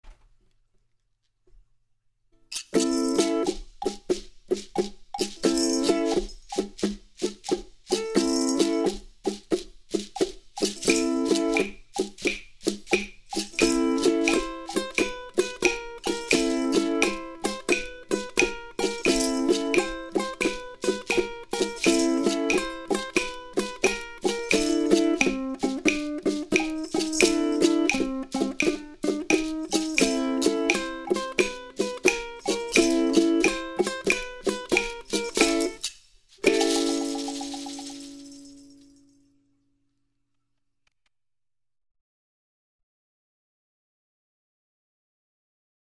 • Tab part - played with a 'swing' feel...1 &2 &3 &4 & in style.
• There is a little riff involving the Am chord moving to the A chord in a swing feel of 'long-short.'
• The rest of the chords in the piece are played in the same 'swing' style.